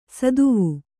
♪ saduvu